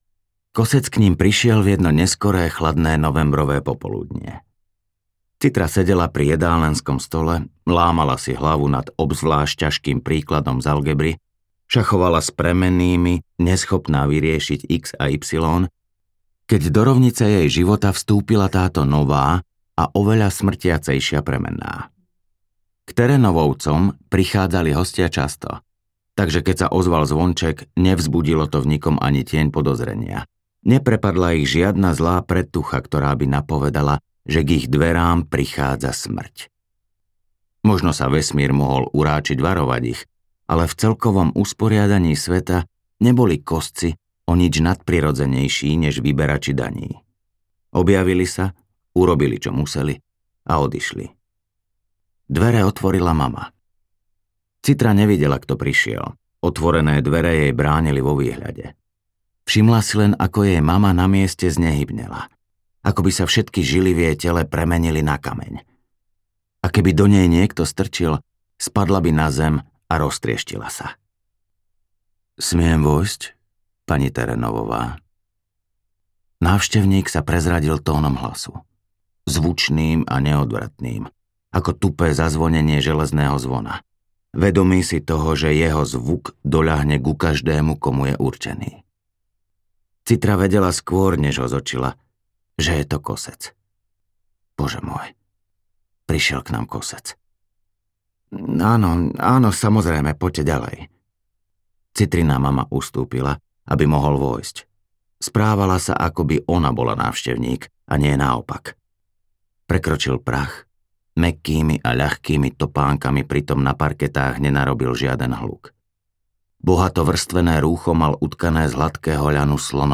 Kosec audiokniha
Ukázka z knihy